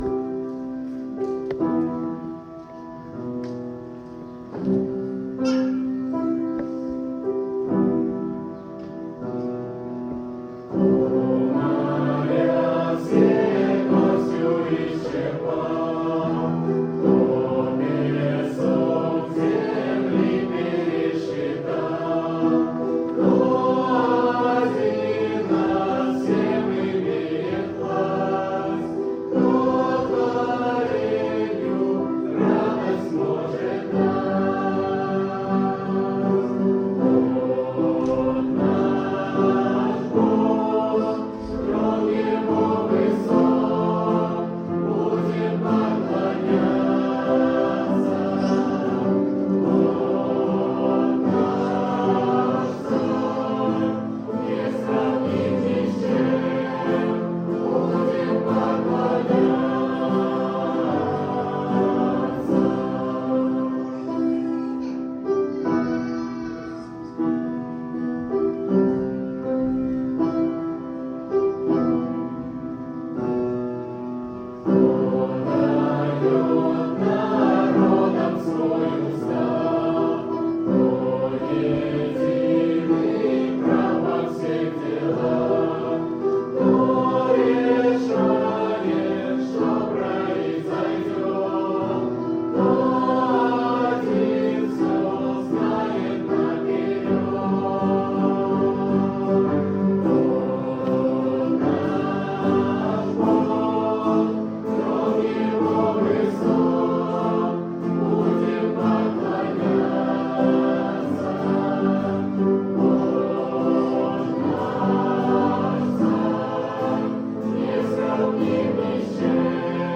|  Пример исполнения 2 | Пение молодежи |